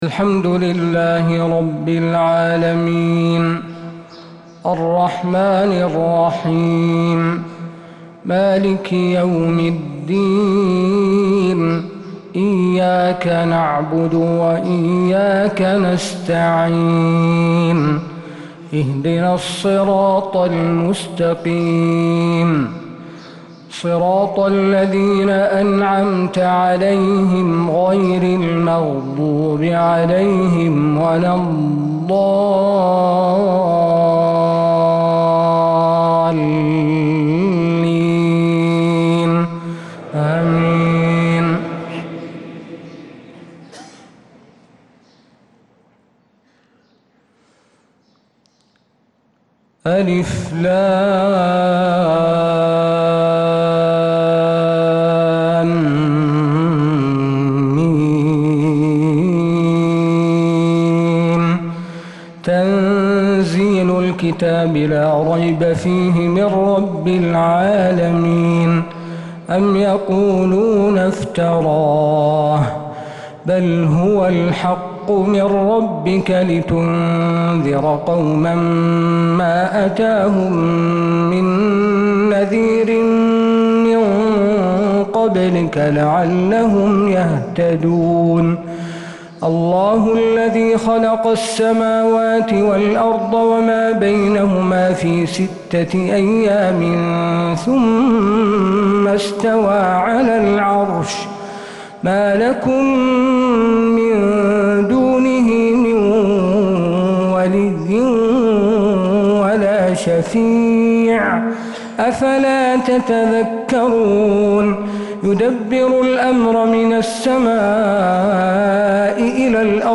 فجر الجمعة 8-8-1446هـ سورتي السجدة و الإنسان كاملة | Fajr prayer Surat as-Sajdah & al-Insane 7-2-2025 > 1446 🕌 > الفروض - تلاوات الحرمين